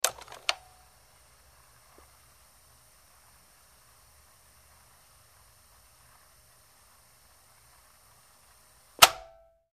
Answering Machine Movement3; Answering Machine Resetting Tape Routine; Starts With A Click Followed By A Tape Being Whirled And Ends With Another Click. Close Perspective